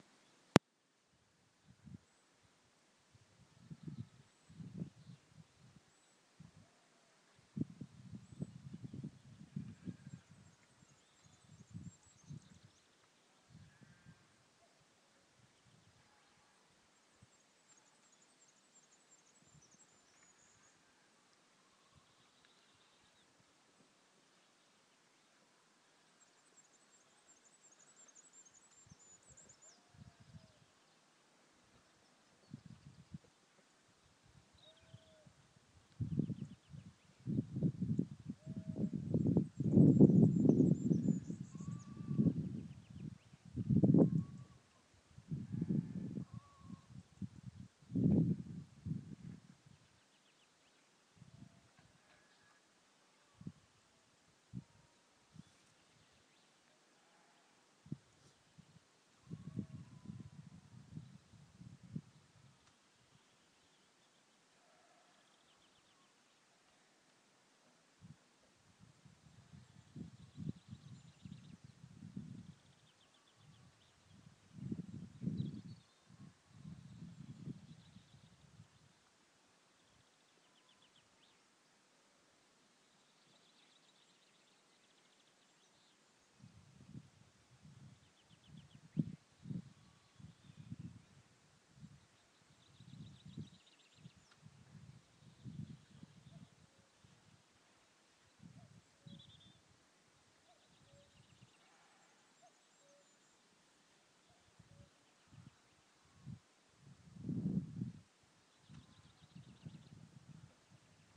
Waking in the countryside